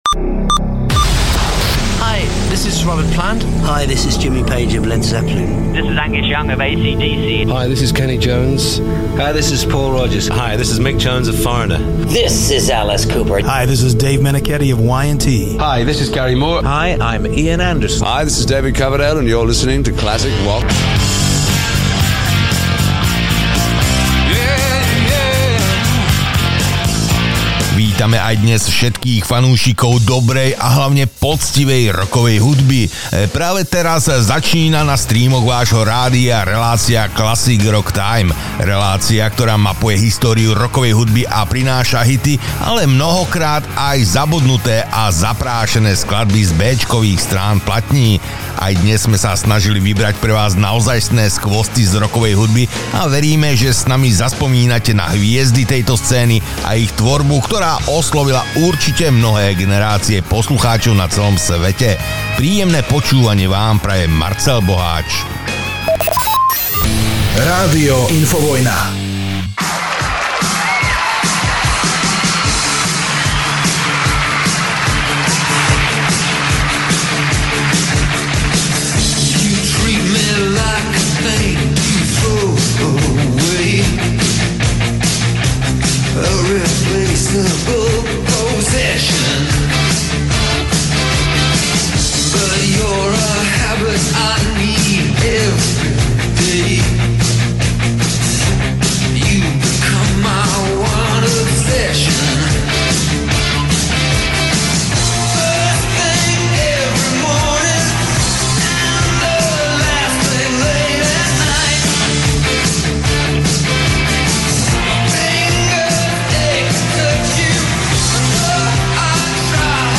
Rocková show plná hitov a nezabudnuteľných skladbieb.
Živé vysielanie